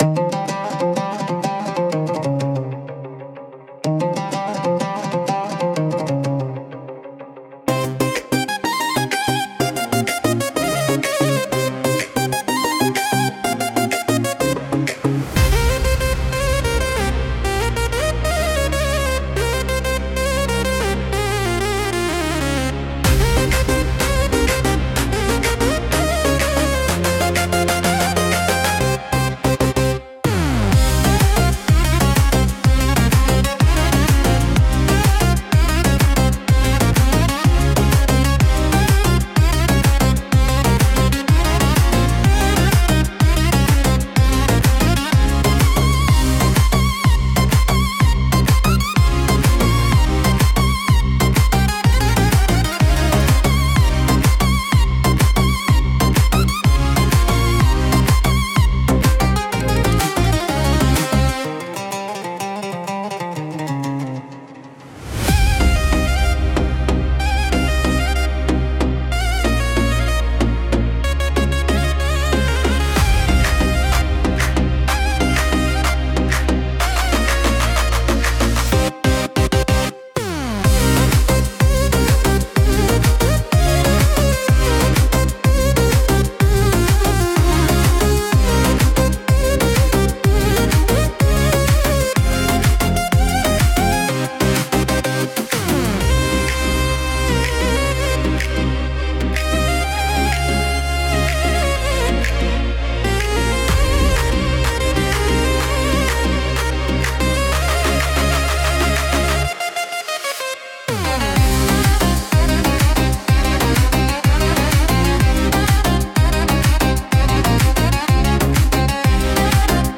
独特のメロディとリズムで聴く人の感覚を刺激します。